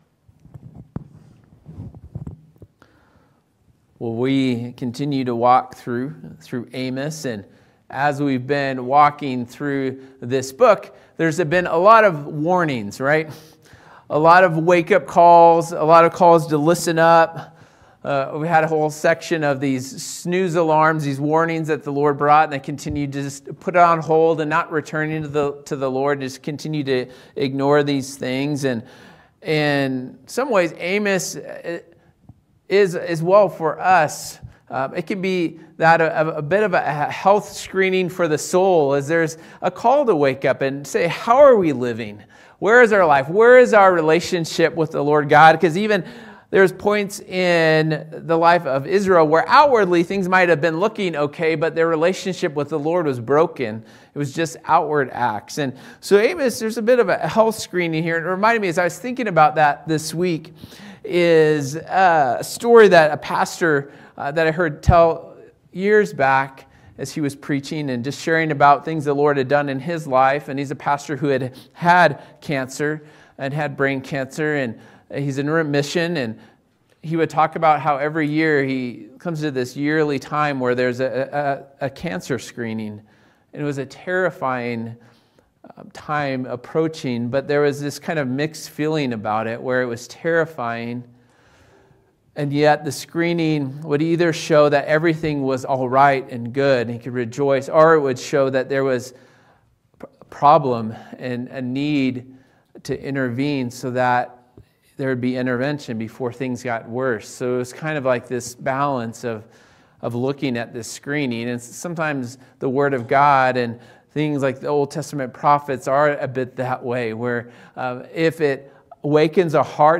Sermons by Calvary Church Derby Hill